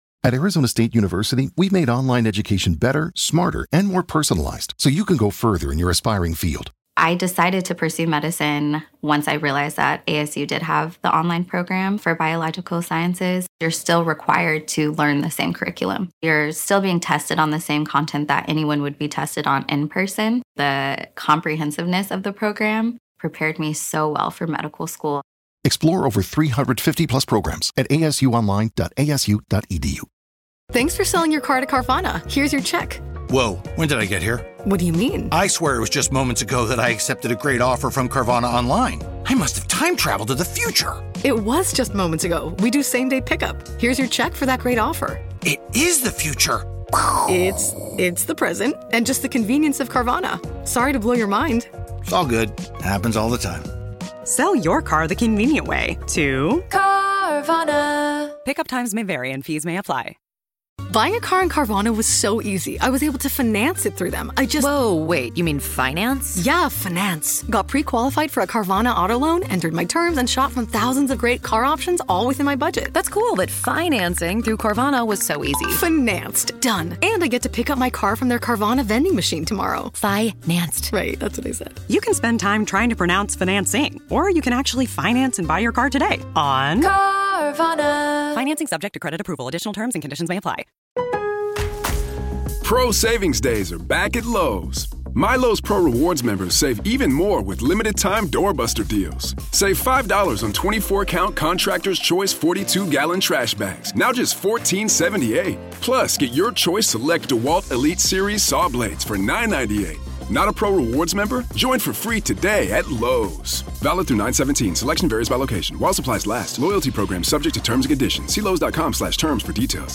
The hosts are two best friends who are chatting about true crime cases in the medical field.